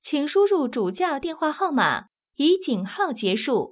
ivr-enter_source_telephone_number.wav